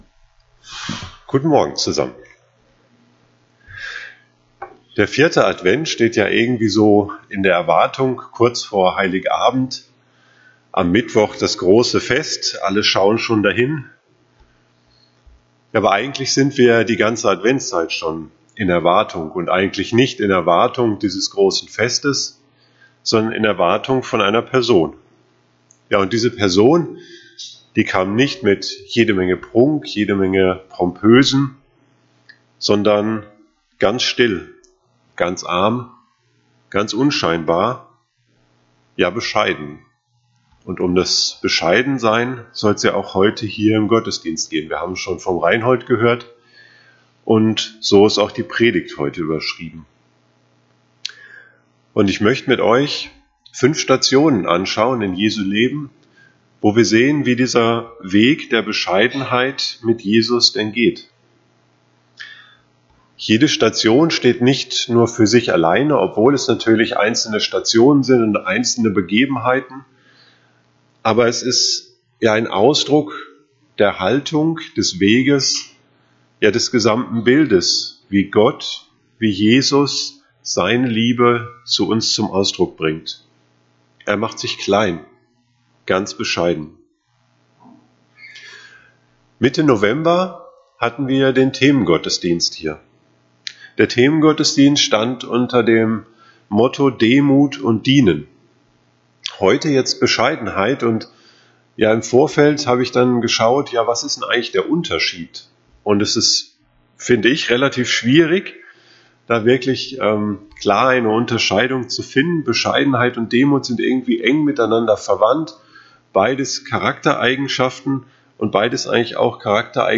Bescheidenheit Prediger